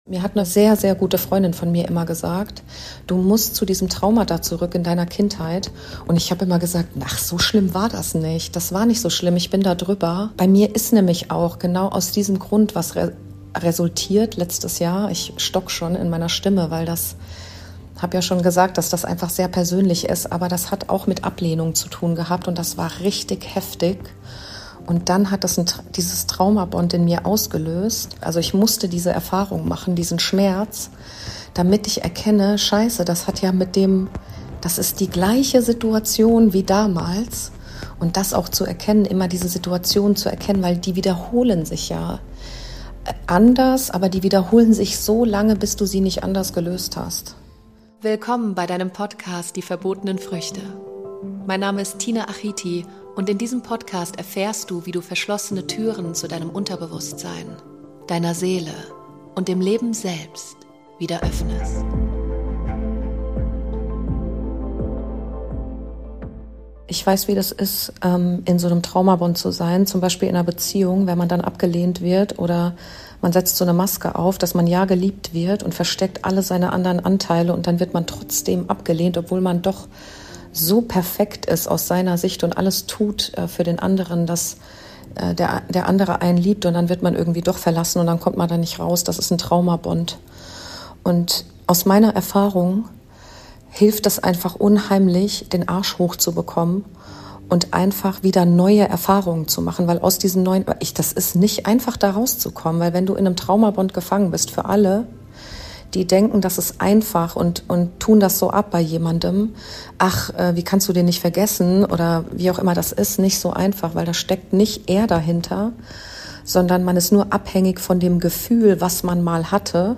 Die Frage, die immer wieder aus der Community kommt: Wie kann ich ihn/sie endlich loslassen. Meine Gedanken und Erfahrungen dazu in dieser Podcast Episode. und verzeiht bitte die Sound Quali.